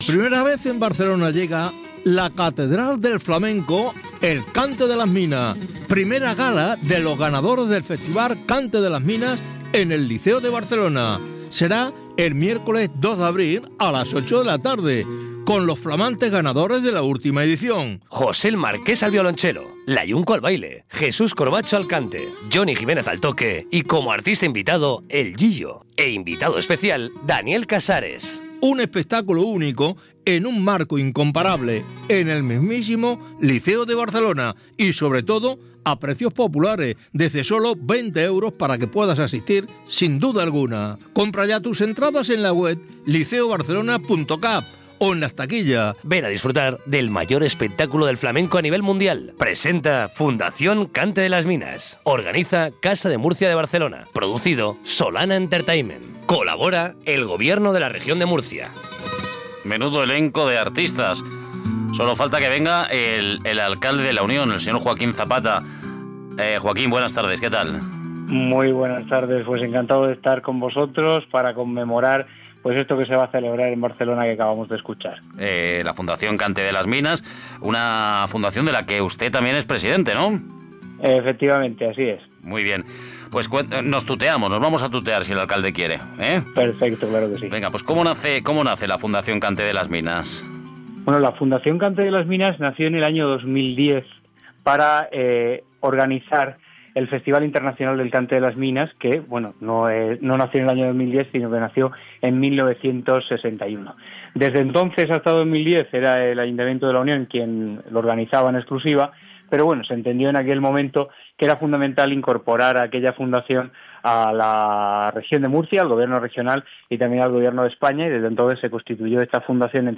entrv-joaquin-zapata.mp3